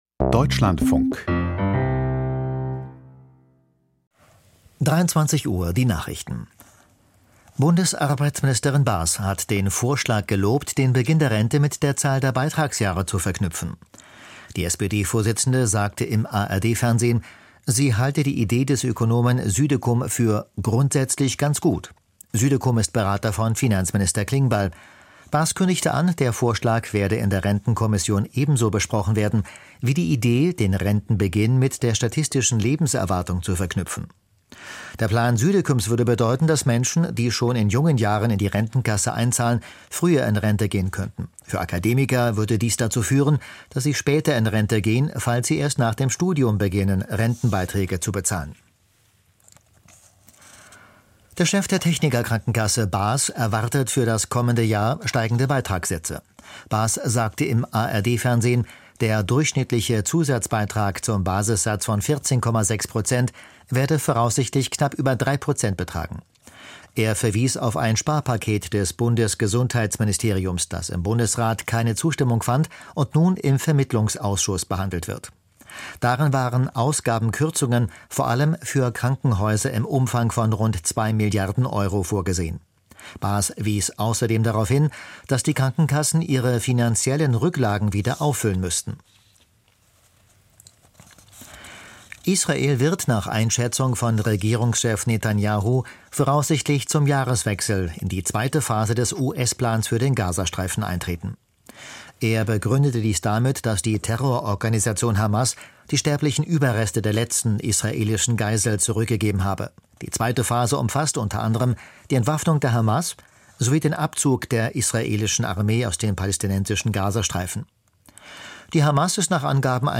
Die Nachrichten vom 07.12.2025, 23:00 Uhr